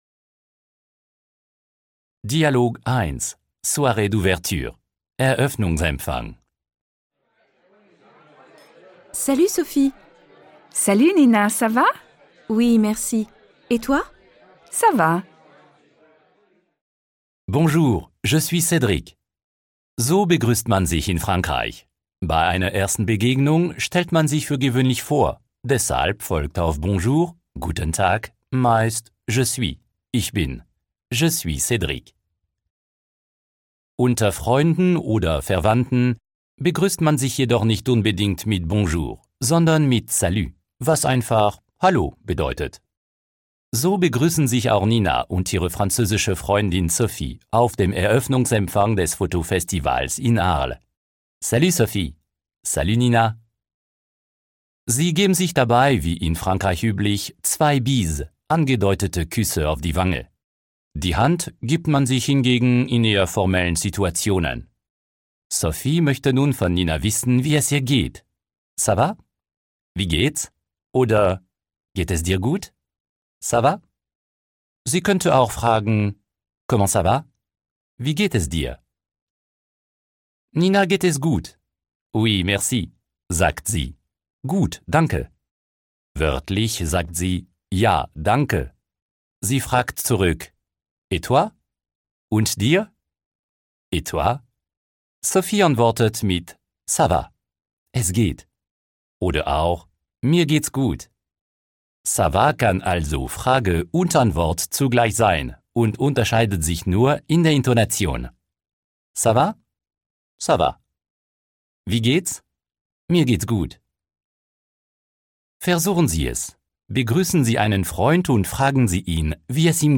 Premium-Audiotraining
Französisch hören und erleben - das Audiotraining mit spannende Reportagen, lebendigen Dialogen und abwechslungsreichen Übungen. Die MP3-Dateien mit Begleitbuch vermitteln in zwölf Kapiteln den wichtigsten Wortschatz, um sich in den gängigsten Alltagssituationen zu verständigen.